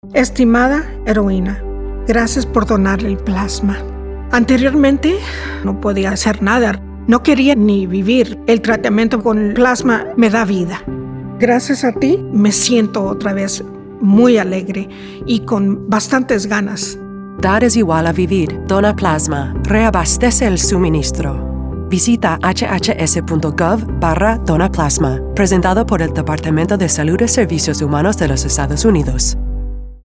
Anuncios de servicio público para la radio